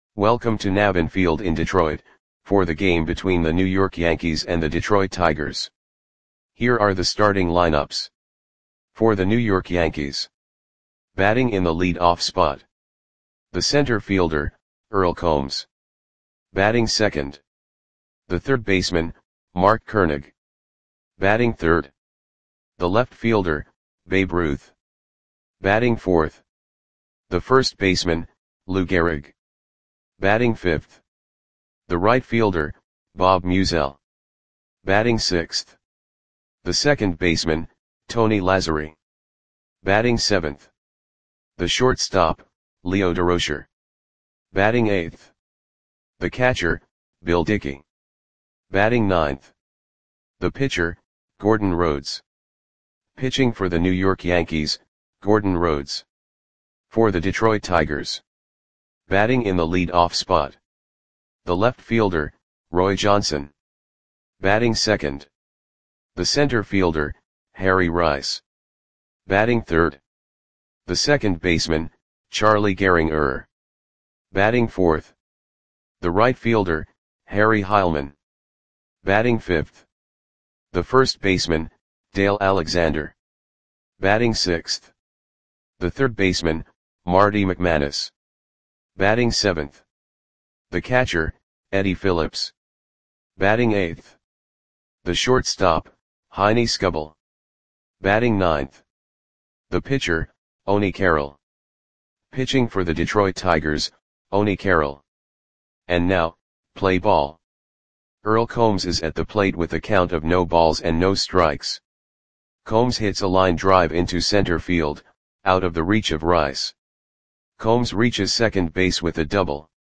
Audio Play-by-Play for Detroit Tigers on May 11, 1929
Click the button below to listen to the audio play-by-play.